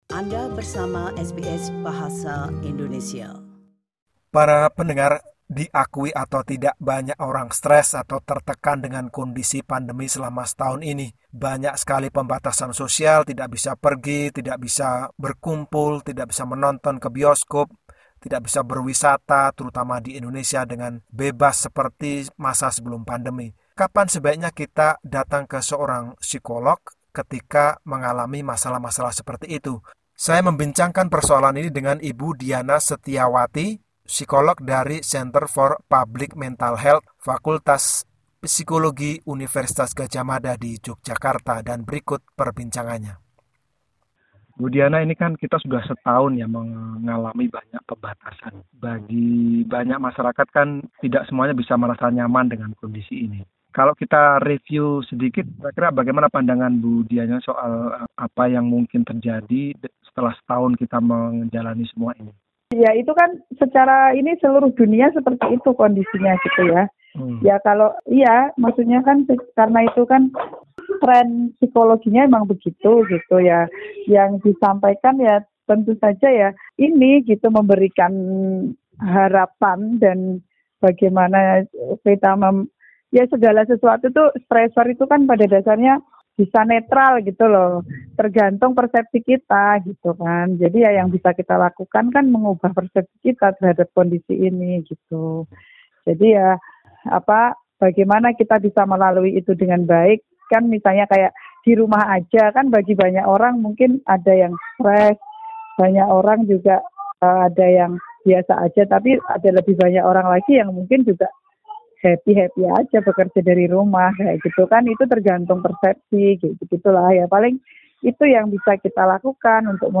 psikolog dari Center for Public Mental Health akan membahas persoalan itu dalam perbincangan berikut ini.